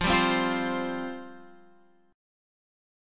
Fsus2 chord